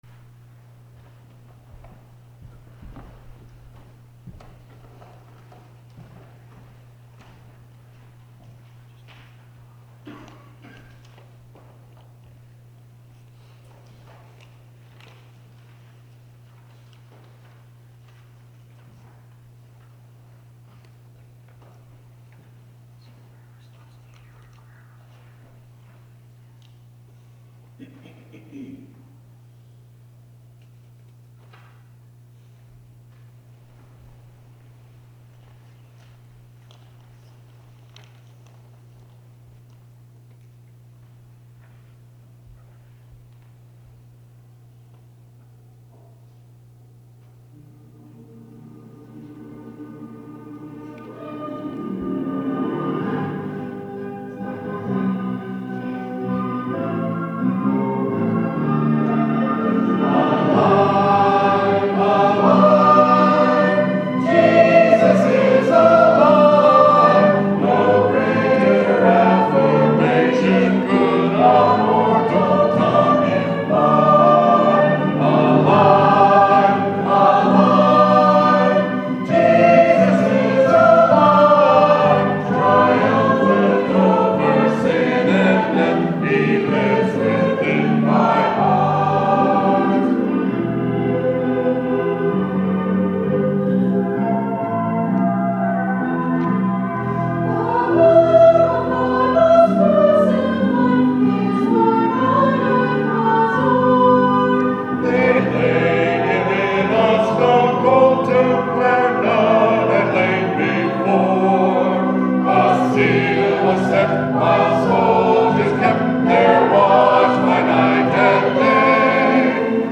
April 9, 2017 — Palm Sunday Easter Choir Cantata
palm-sunday-cantata1-1.mp3